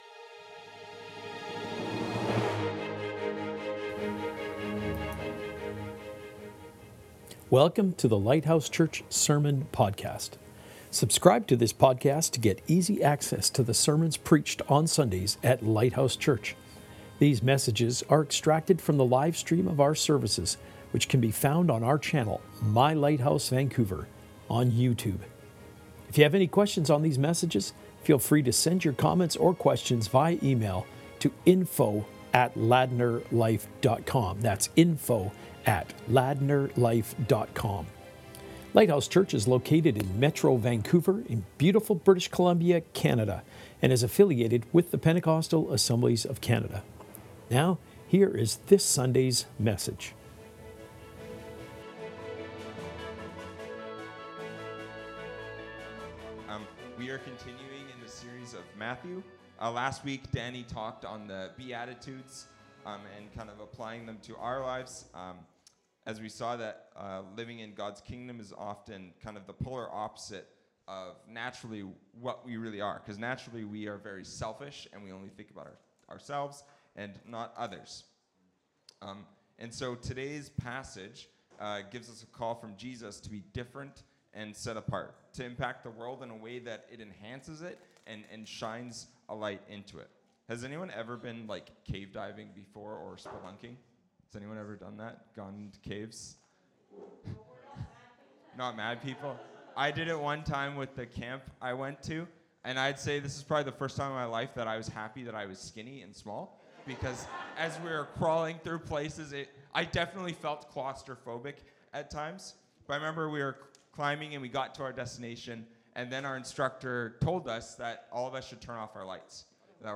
Sermons | Lighthouse Church